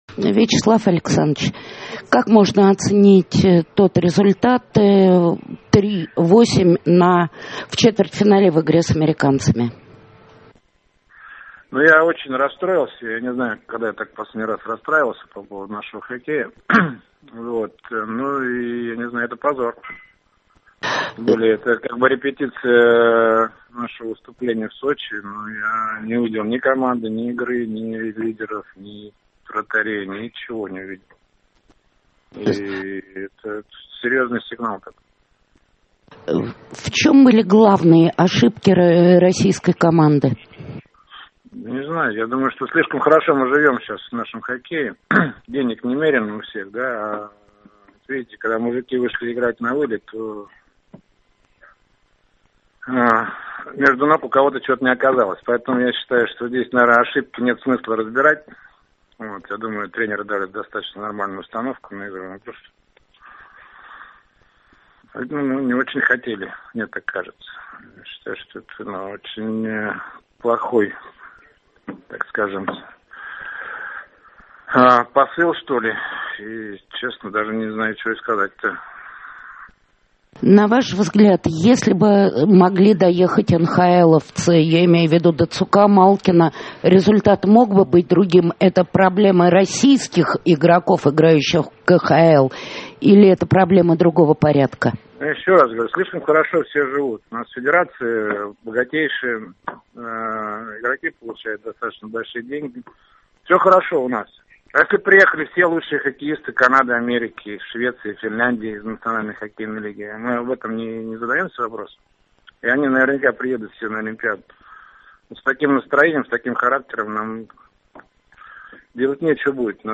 Интервью
О поражении сборной России по хоккею - Вячеслав Фетисов - Интервью - 2013-05-17